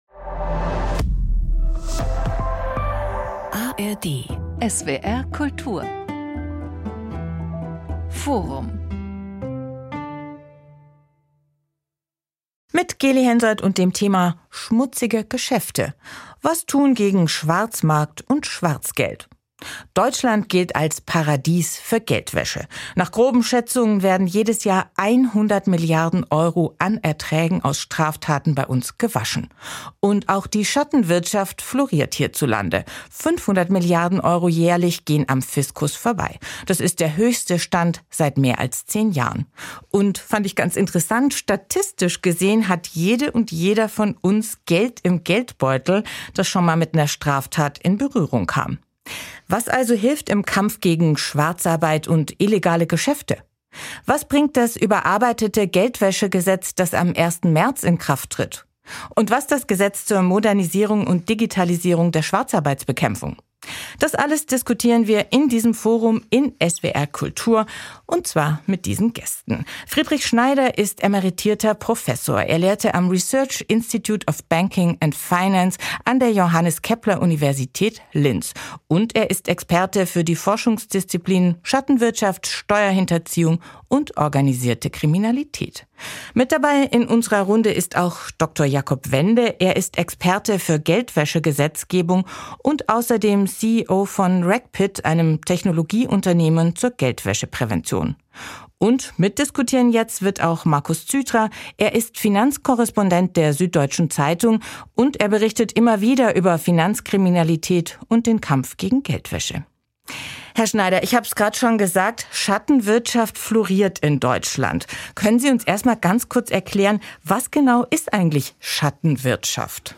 Experte für Geldwäsche-Gesetzgebung